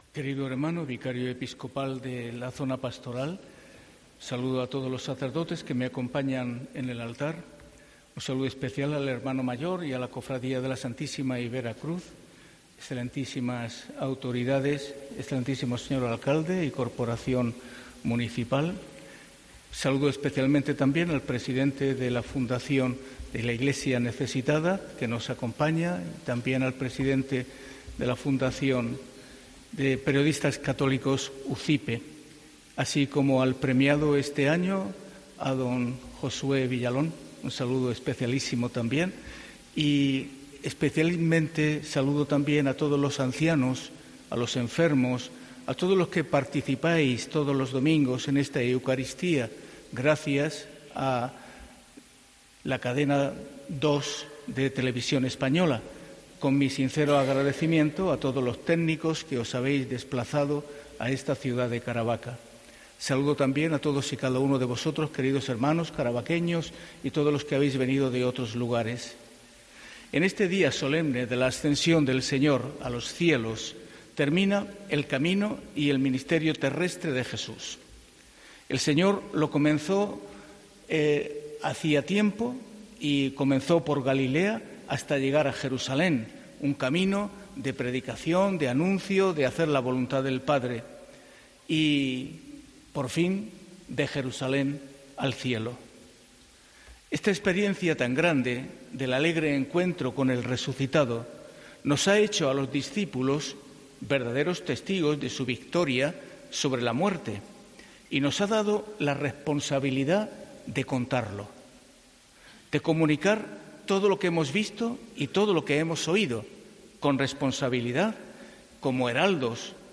Escucha la homilía del obispo de Cartagena en la Jornada de las Comunicaciones Sociales
José Manuel Lorca Planes, obispo de Cartagena y presidente de la Comisión Episcopal para las Comunicaciones Sociales de la Conferencia Episcopal Española, ha presidido este domingo la Misa en la basílica menor de la Vera Cruz de Caravaca.